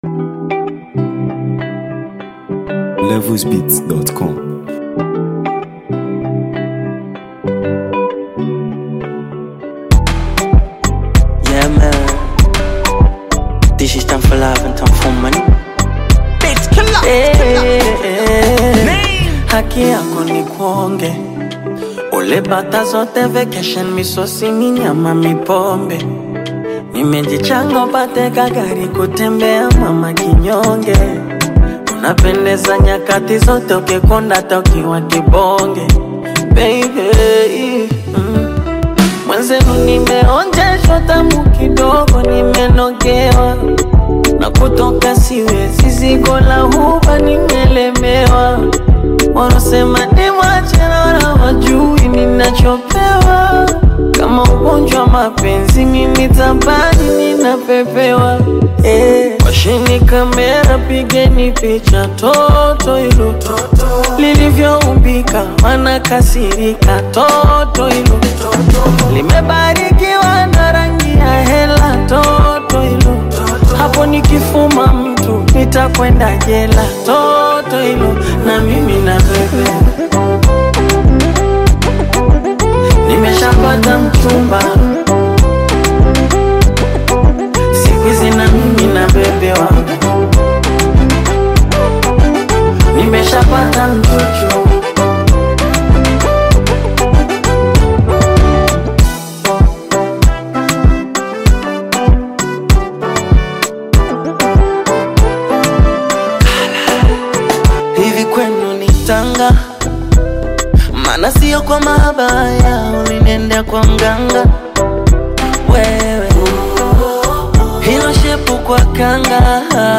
A Sizzling Tanzanian Anthem You Must Hear
sizzling and energetic new sound